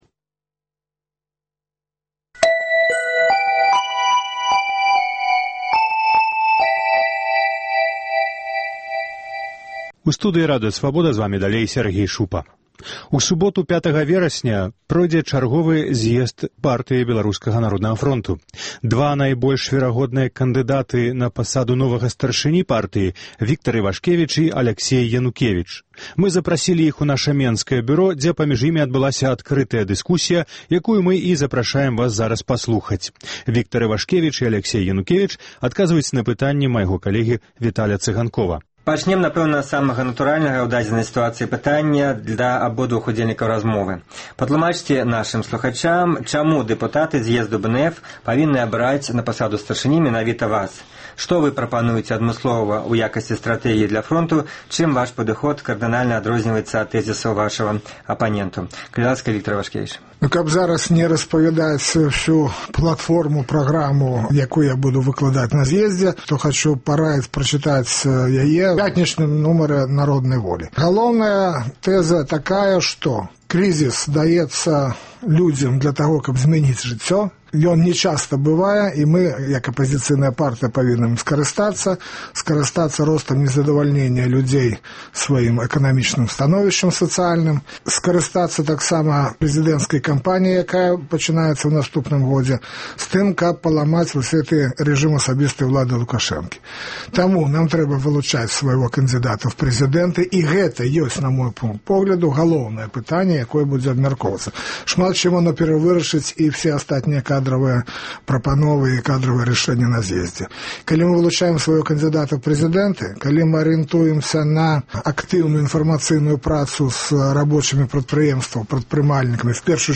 Дэбаты